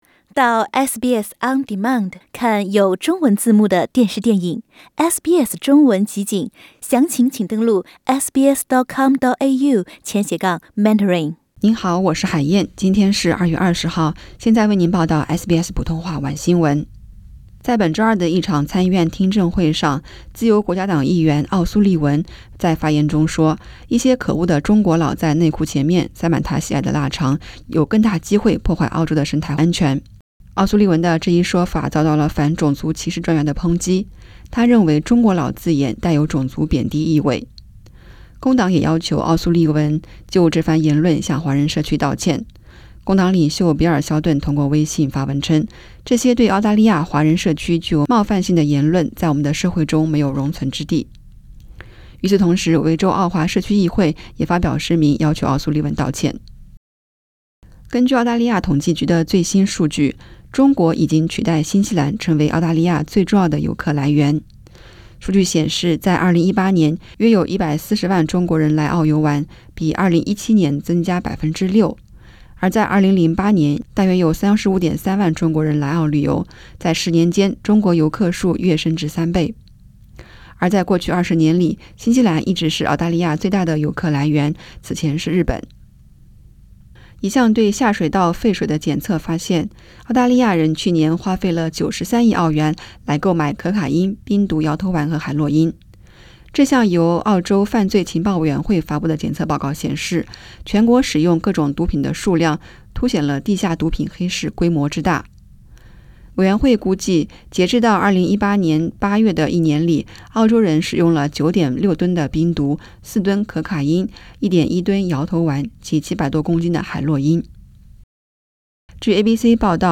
SBS晚新聞（2月20日）